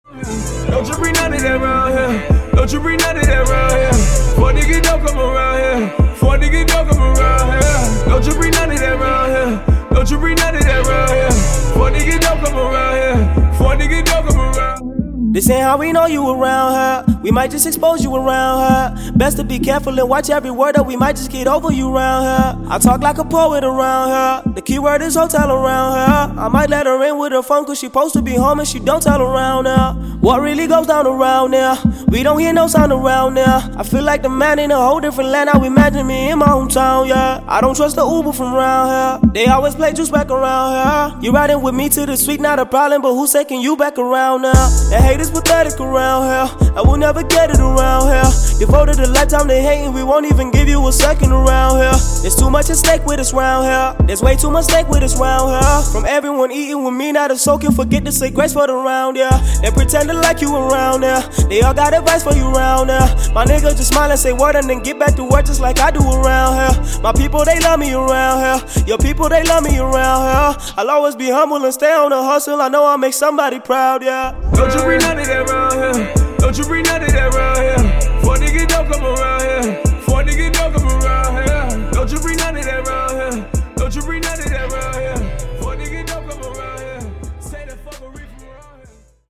a trap song
raps some heartfelt lines